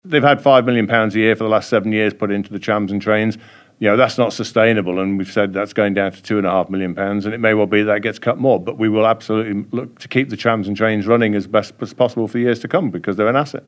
Tim Crookall says we've got to realise what the current priorities are: